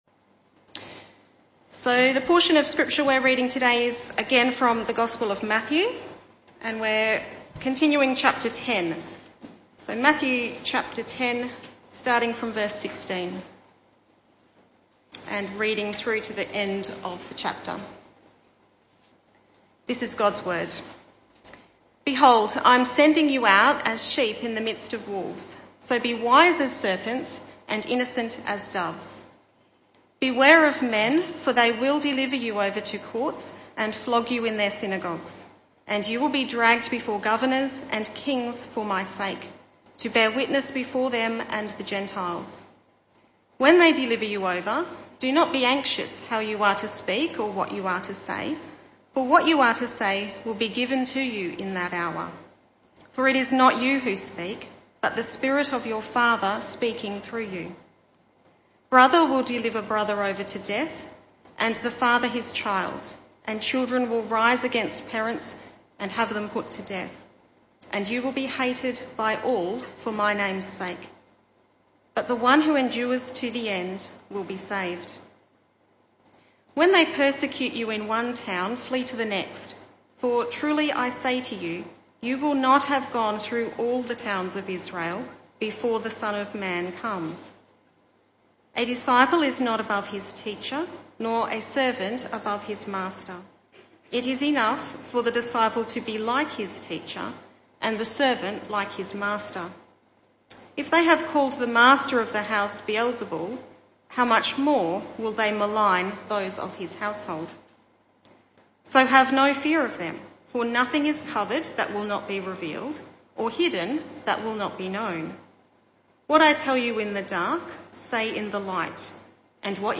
This talk was part of the AM Service series entitled The Message Of Matthew.